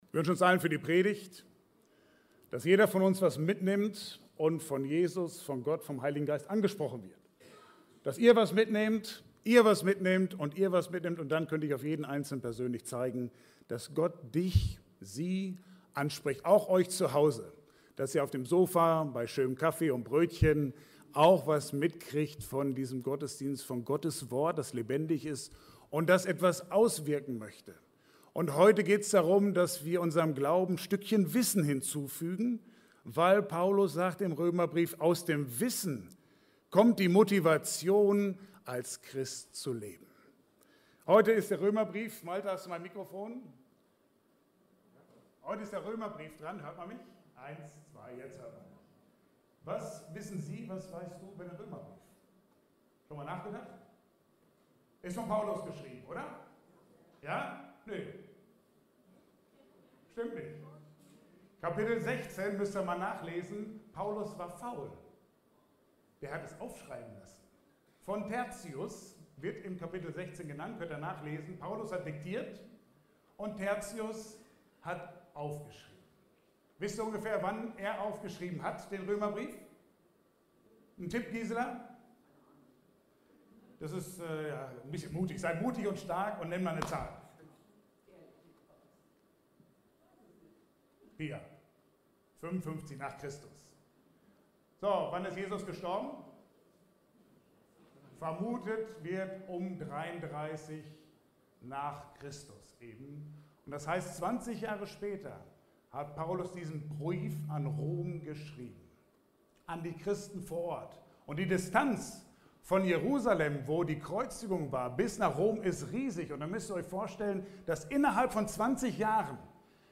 Passage: Römer 6,3–8 Dienstart: Gottesdienst « Weißt Du was morgen passiert?